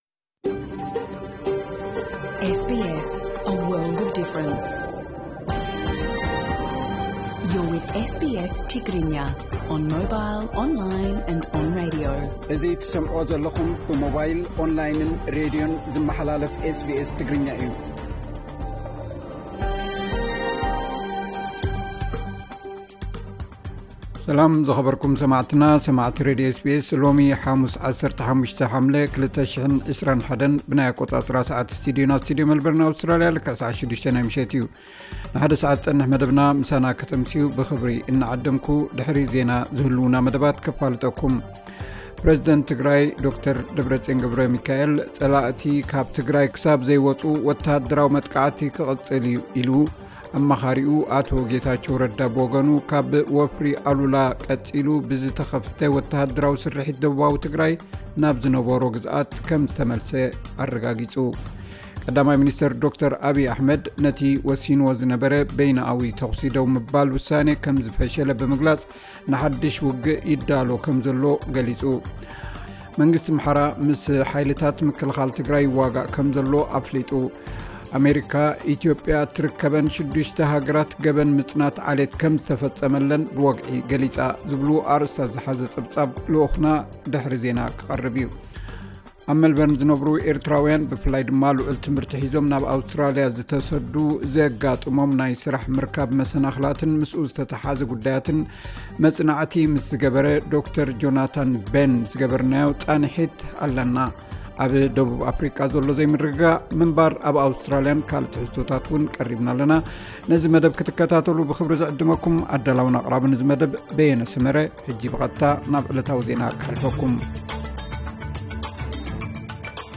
ዕለታዊ ዜና 15 ሓምለ 2021 SBS ትግርኛ